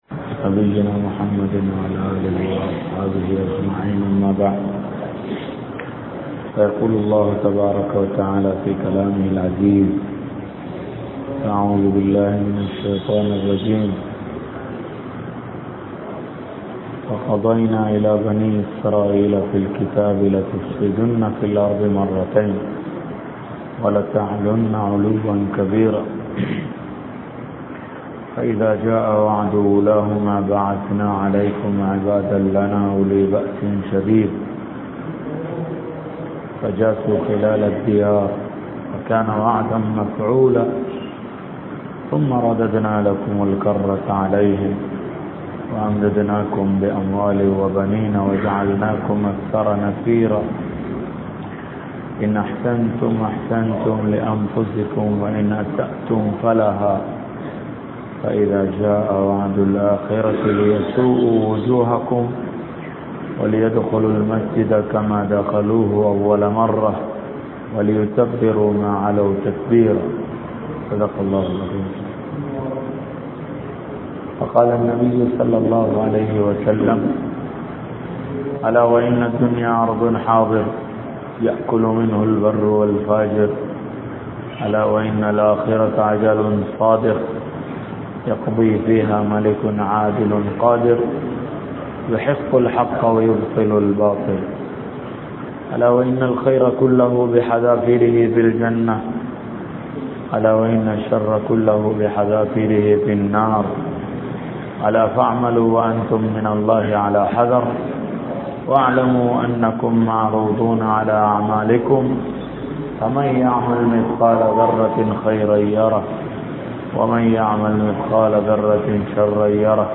Yaarudaiya Vali Near Vali? (யாருடைய வழி நேர் வழி?) | Audio Bayans | All Ceylon Muslim Youth Community | Addalaichenai